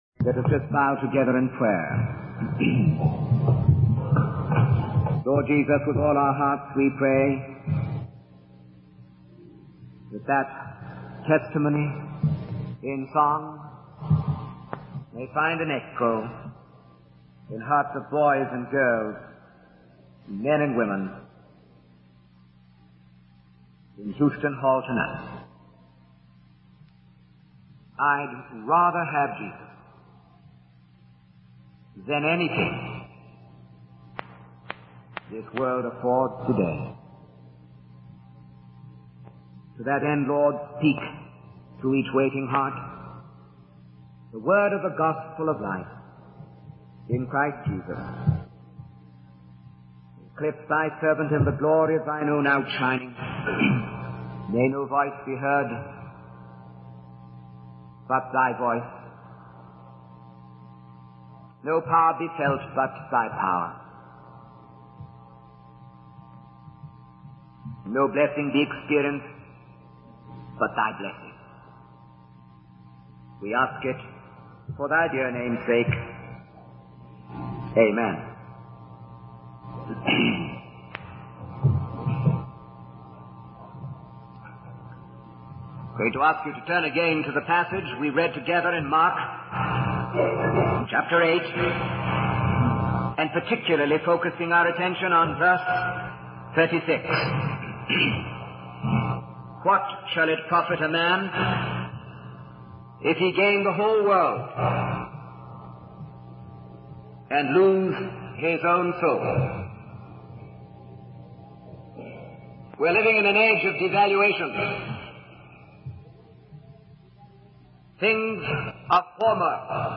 In this sermon, the speaker shares his personal journey of being called by God to serve in Angola and Congo.